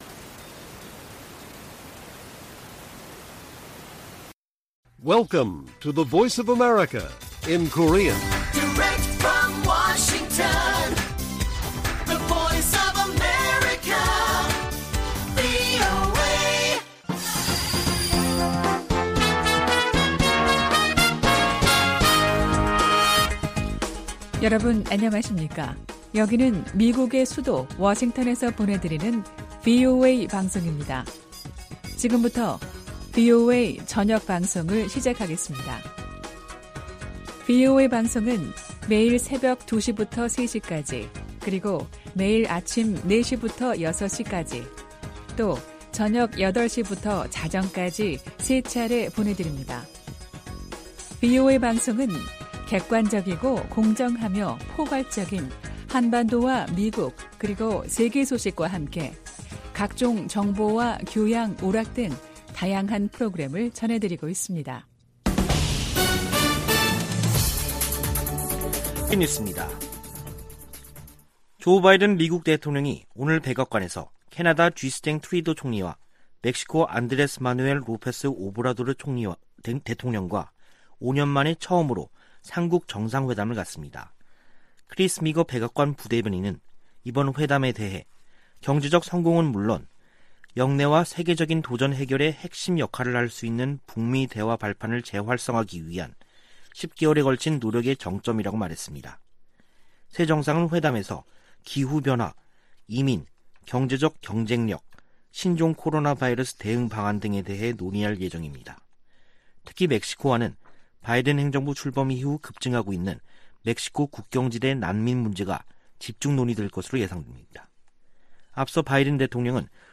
VOA 한국어 간판 뉴스 프로그램 '뉴스 투데이', 2021년 11월 18일 1부 방송입니다. 유엔총회 제3위원회가 북한 내 인권 침해에 대한 책임 추궁 등을 강조한 북한인권 결의안을 표결 없이 합의 채택했습니다. 미국 국무부가 북한을 종교자유 특별우려국으로 재지정했습니다.